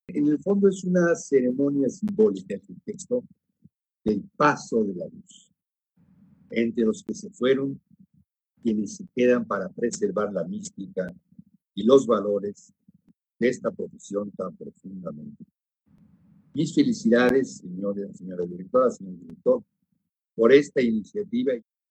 En reunión virtual, Graue presidió la presentación de la obra prologada por él, la cual integra un conjunto de testimonios de enfermeras y enfermeros (desde estudiantes hasta directivos) que se han enfrentado a partir de marzo a la emergencia sanitaria.